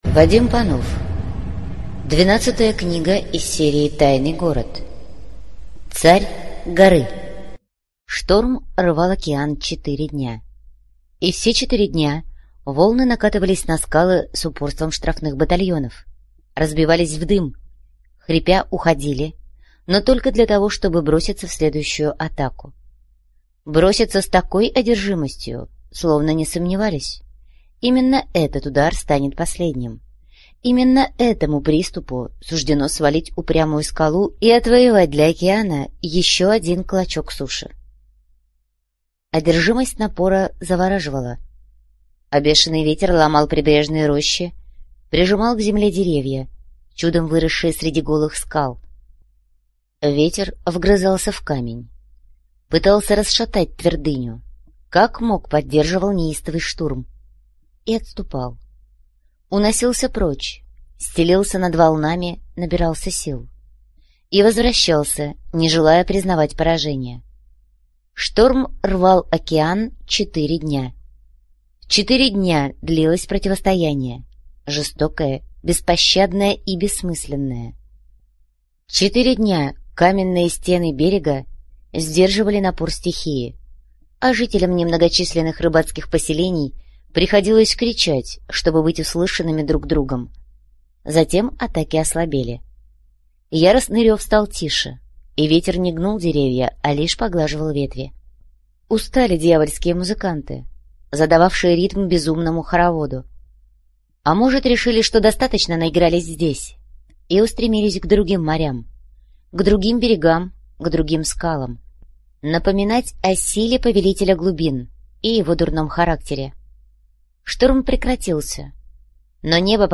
Аудиокнига Царь горы - купить, скачать и слушать онлайн | КнигоПоиск